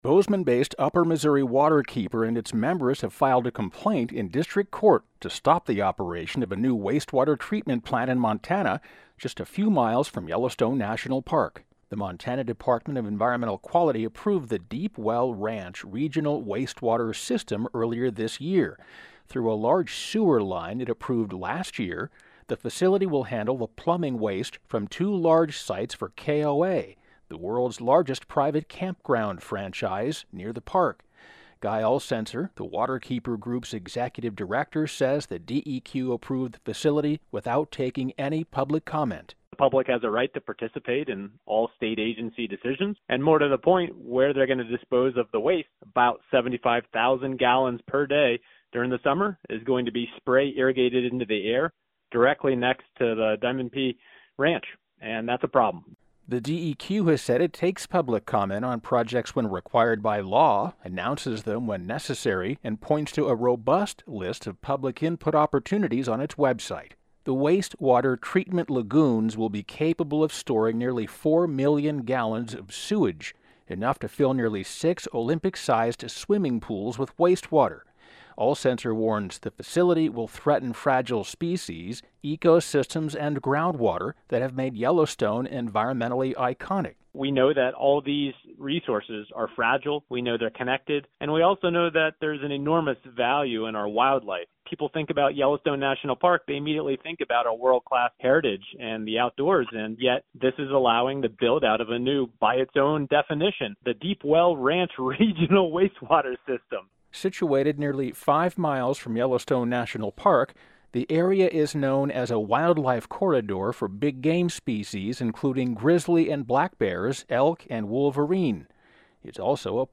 Producer-Editor